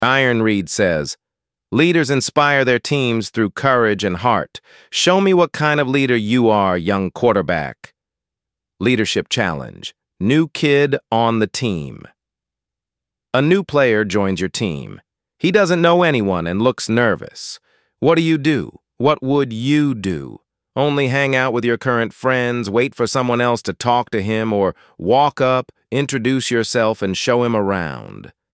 ElevenLabs_2026-01-27T15_26_29_Adam – Dominant, Firm_pre_sp89_s41_sb48_se0_b_m2